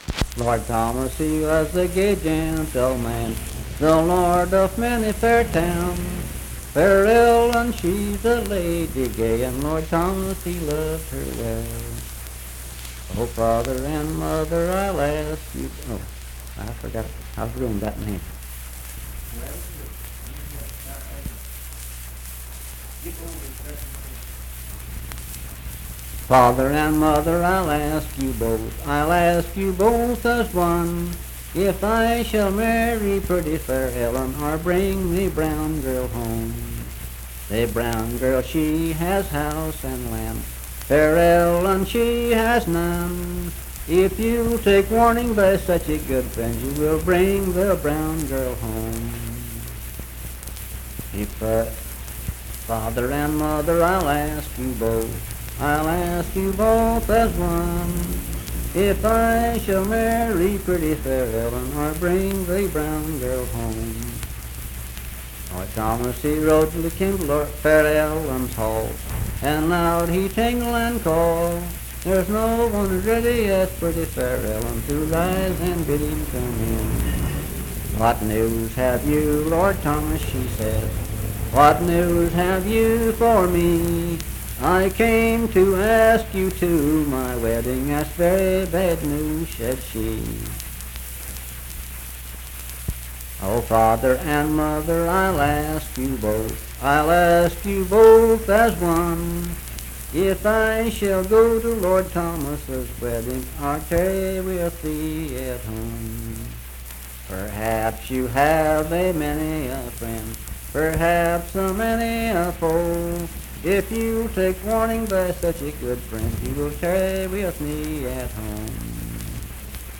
Unaccompanied vocal music performance
Verse-refrain 19(4).
Voice (sung)